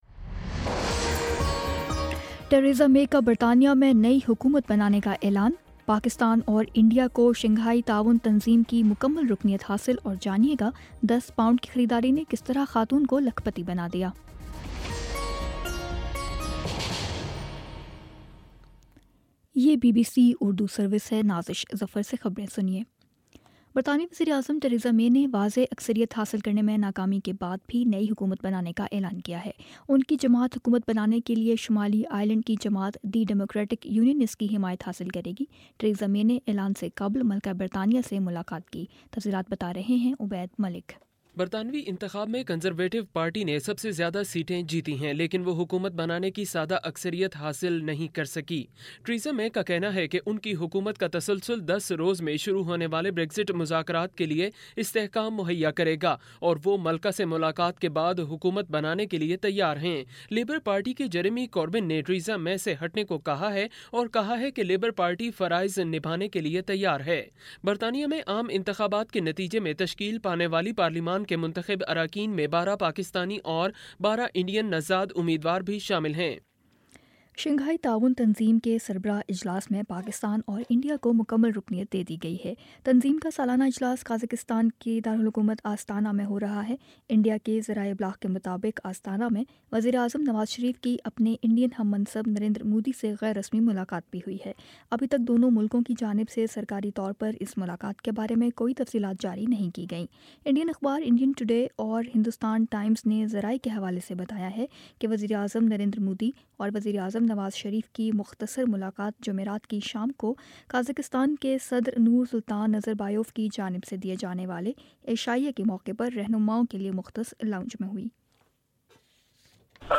جون 09 : شام سات بجے کا نیوز بُلیٹن